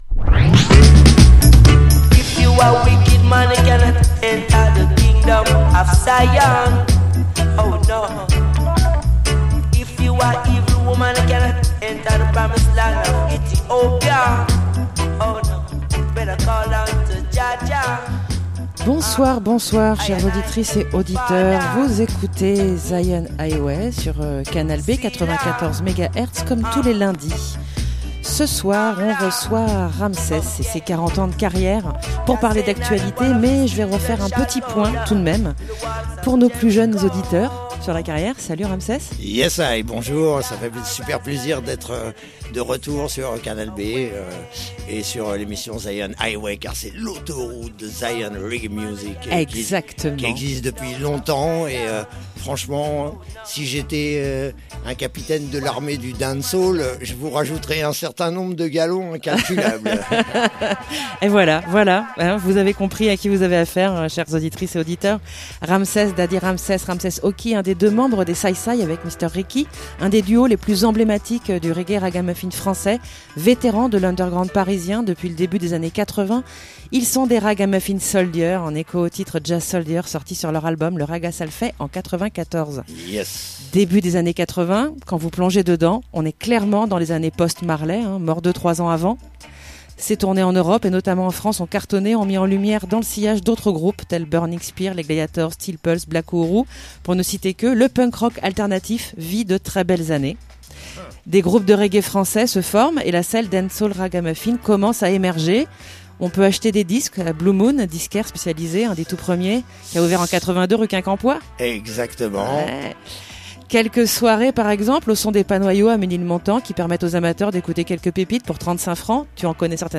Vétérans de l’underground parisien, depuis le début des années 1980, ils sont des raggamuffins soldiers en echo au titre jah soldier sorti sur leur album Le ragga ça l’fait, sorti en 1994. 1h30 d'interview pendant laquelle, on refait le match!